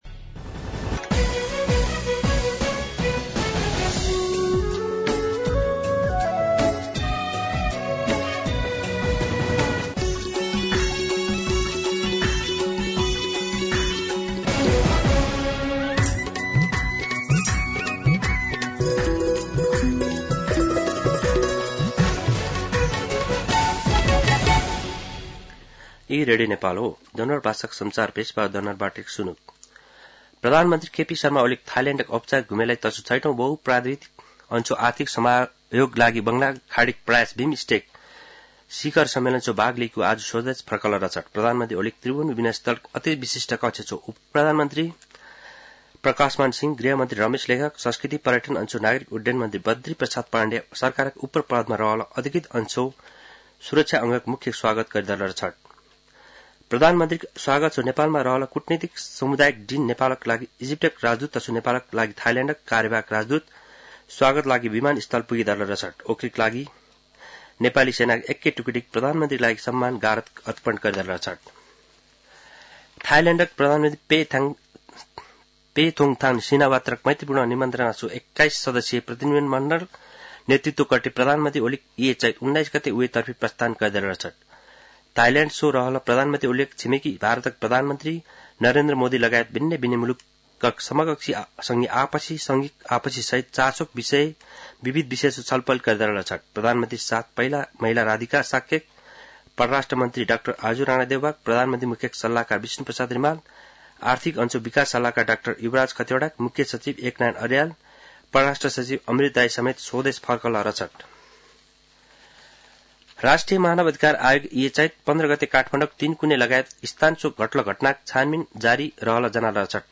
दनुवार भाषामा समाचार : २३ चैत , २०८१
Danuwar-News-2.mp3